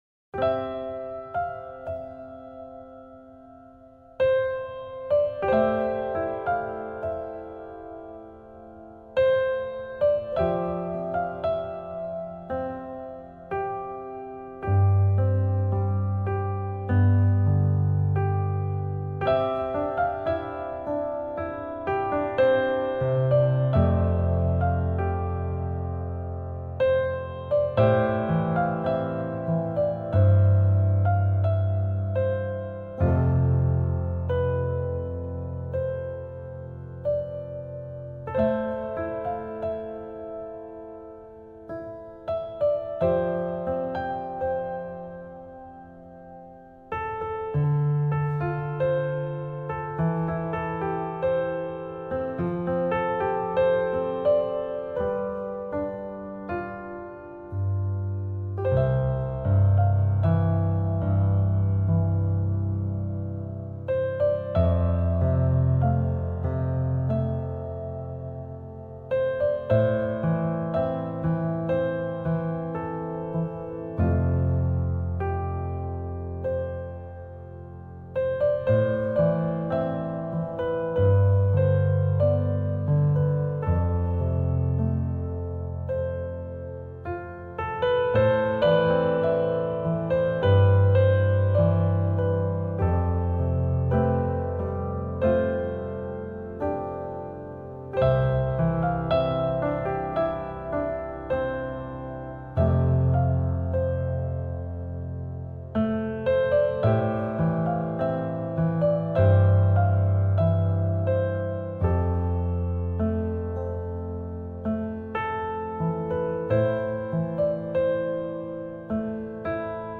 ژانر: عصر نو، مینیمال